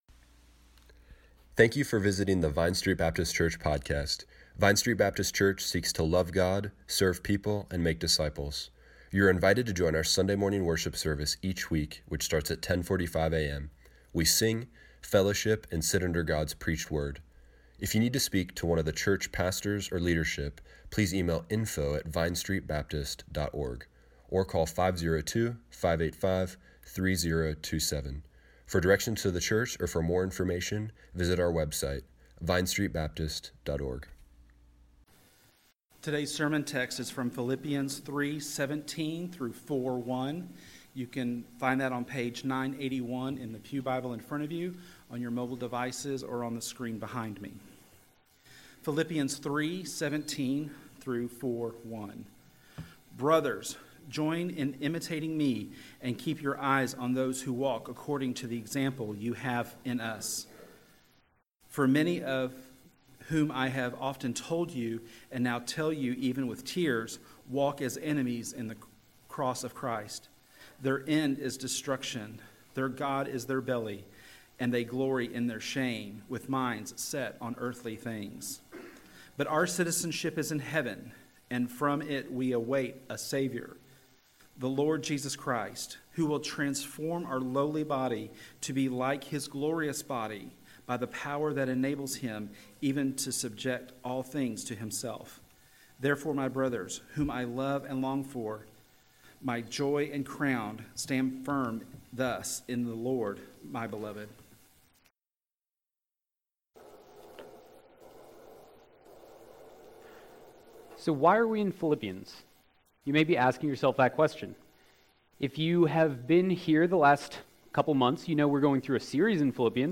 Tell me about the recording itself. A Study in Unity Service Morning Worship Tweet Summary April 21, 2019 – Easter Sunday Click here to listen to the sermon online.